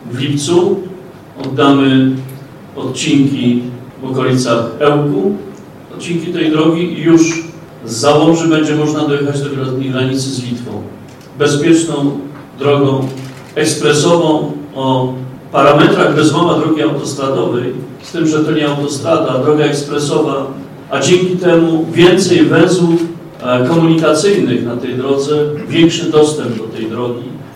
,,Mamy nadzieję, że w przyszłym roku inwestycja zostanie zakończona’’ – mówił minister infrastruktury Andrzej Adamczyk na konferencji w Starostwie Powiatowym w Łomży o realizacji budowy Via Baltica.
Minister infrastruktury mówi, że niedługo zostaną otwarte kolejne odcinki trasy.